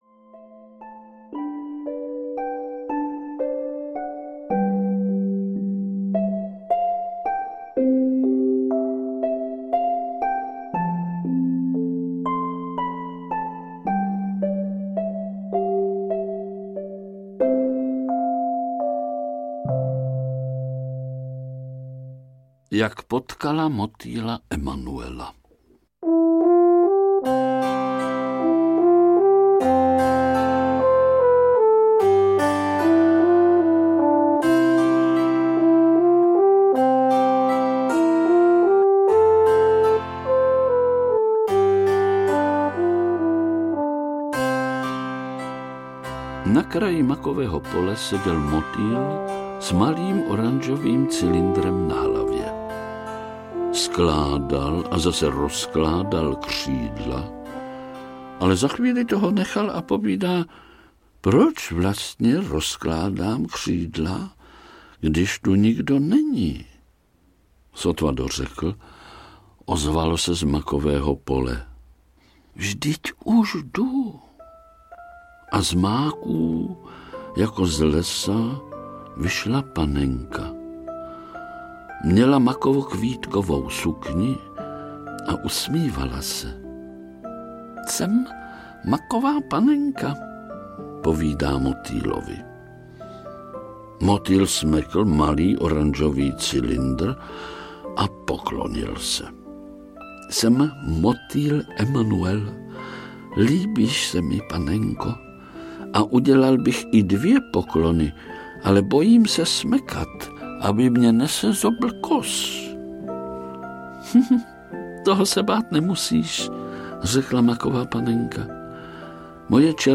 Maková panenka audiokniha
Audiokniha Maková panenka, kterou napsal Václav Čtvrtek. Literární předloha jednoho z nejoblíbenějších televizních večerníčků v interpretaci Vlastimila Brodského.
Ukázka z knihy